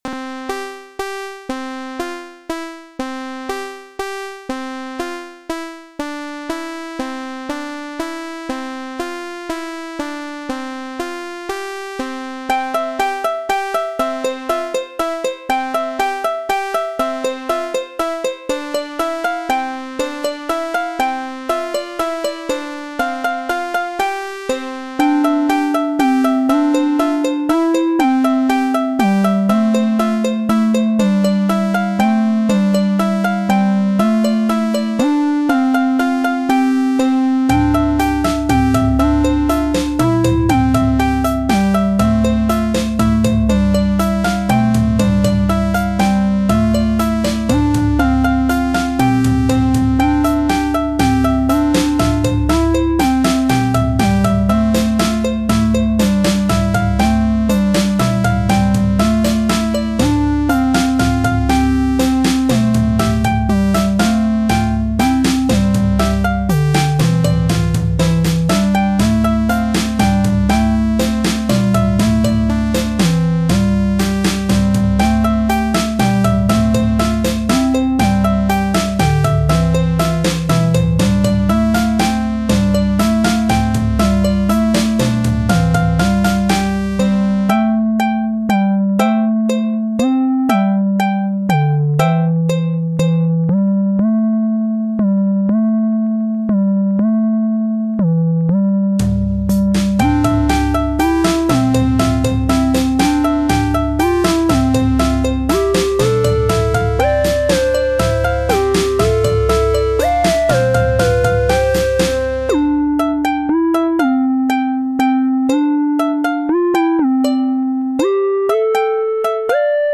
Host made with Chiptune instruments – Made with Bandlab